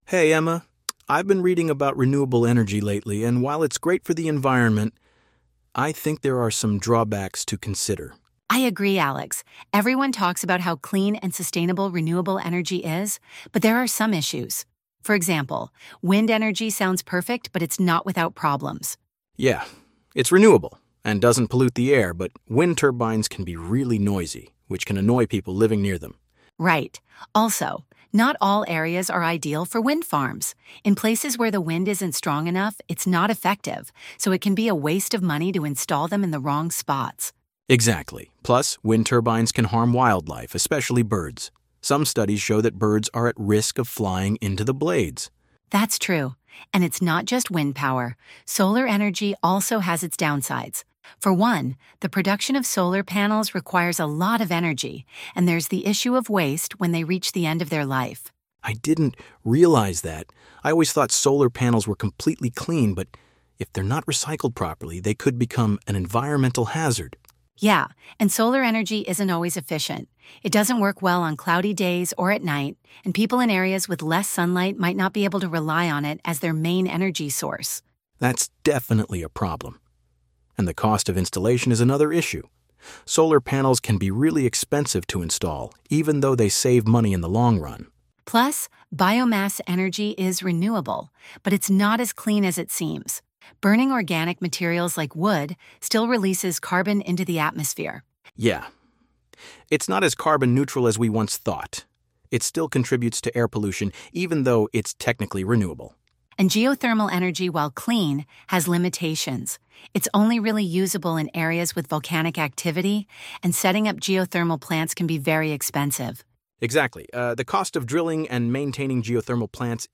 Unit-8-Conversation.mp3